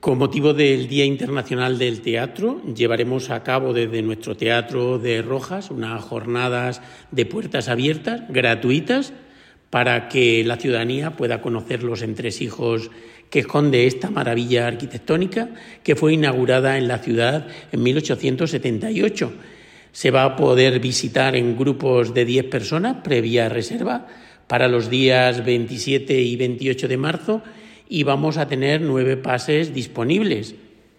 Para ello, se han establecido diferentes horarios, como ha explicado el concejal de Cultura, Teo García.